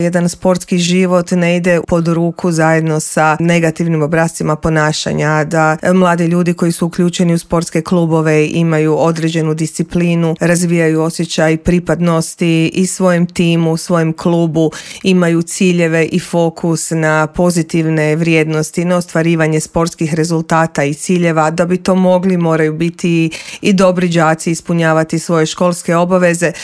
Više o samoj kampanji u intervjuu Media servisa